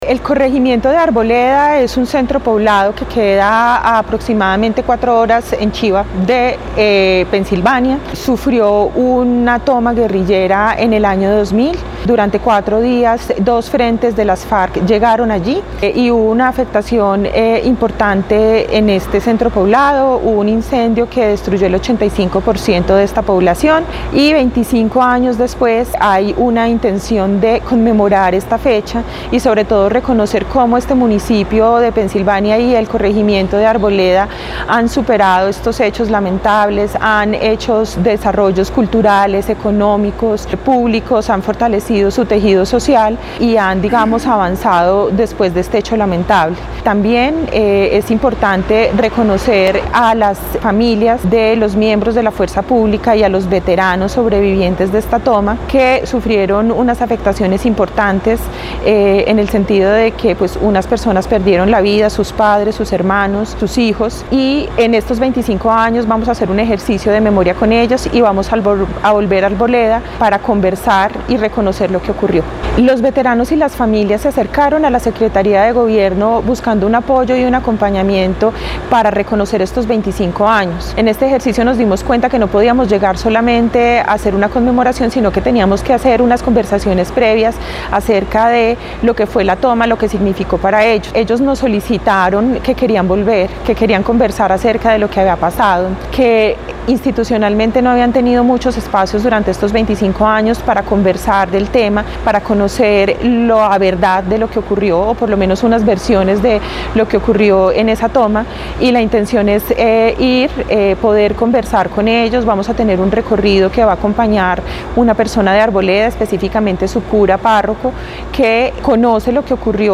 Lina María Salazar Zuluaga, secretaria de Gobierno (e) de Caldas.